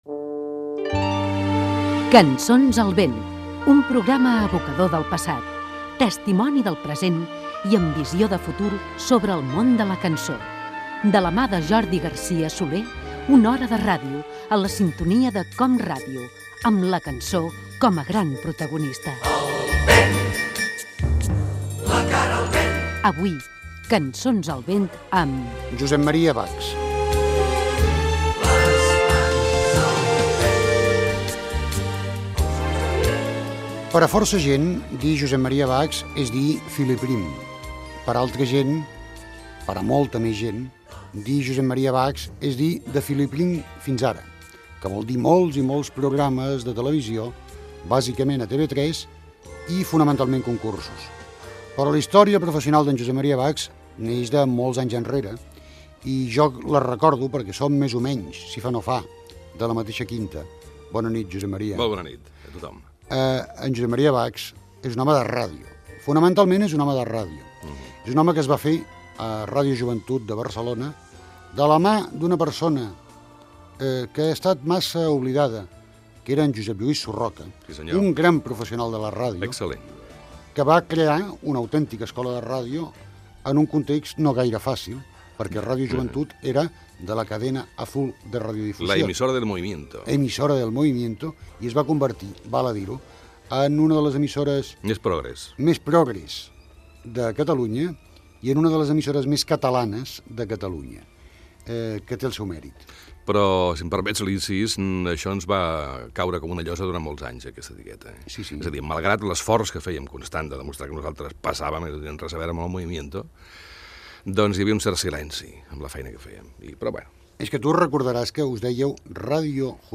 Careta del programa, presentació i fragment d'una entrevista
Divulgació